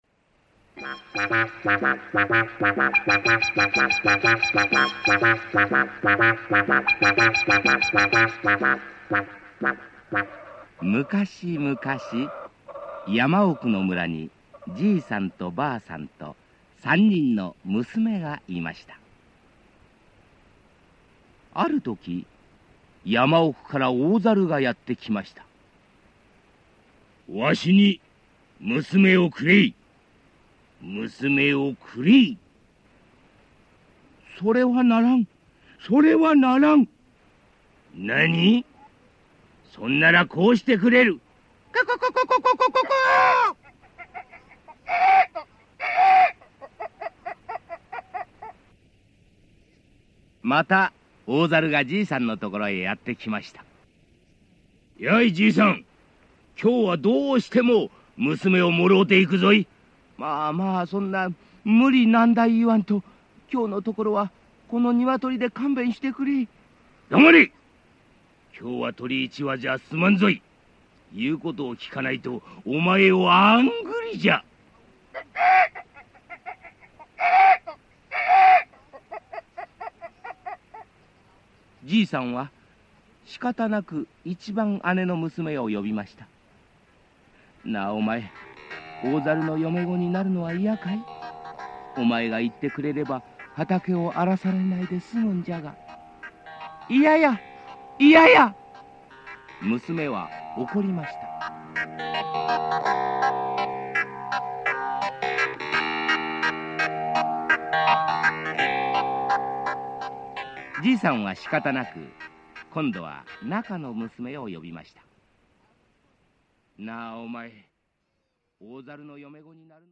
[オーディオブック] さるむこたいじ